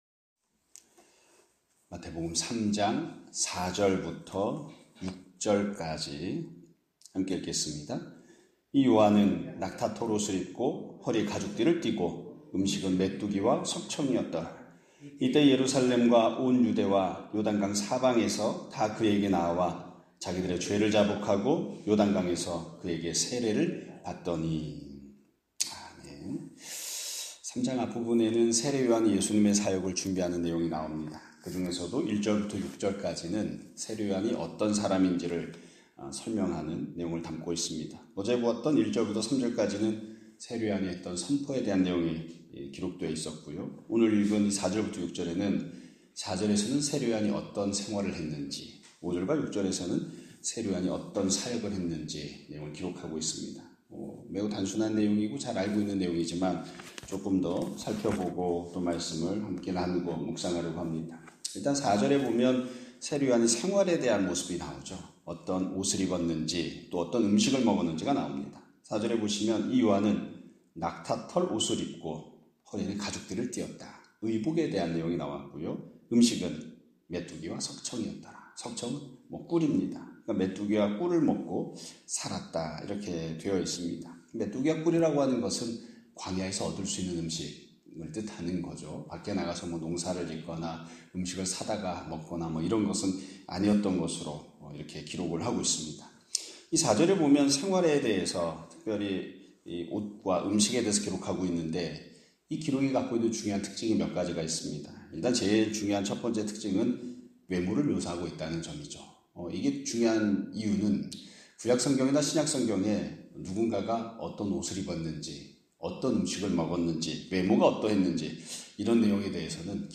2025년 4월 11일(금요일) <아침예배> 설교입니다.